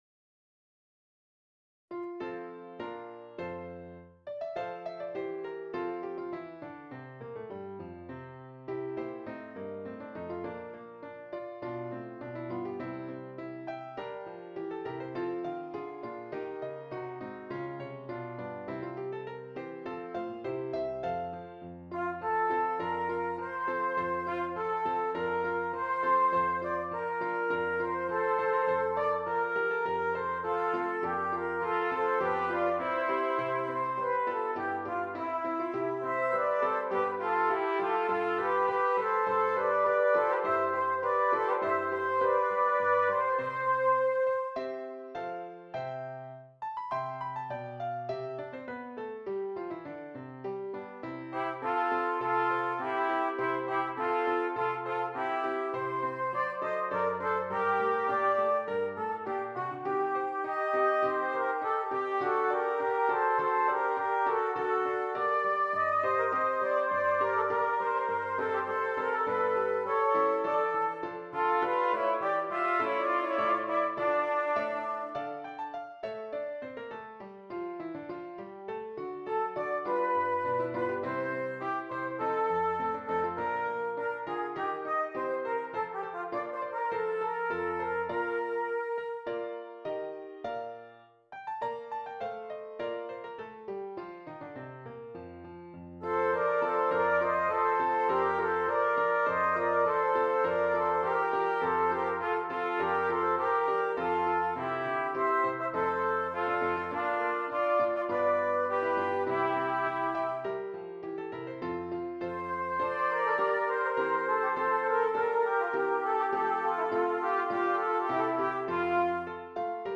2 Trumpets and Keyboard
arranged here for 2 trumpets and keyboard (organ or piano)